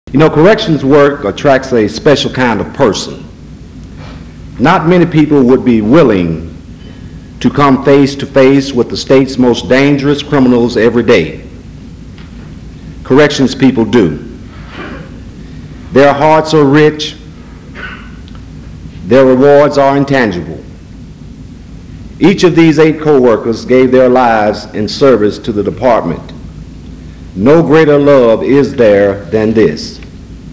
North Carolina Correctional Officers Memorial Service
Fairview Baptist Church
Remarks by Theodis Beck, Secretary of Correction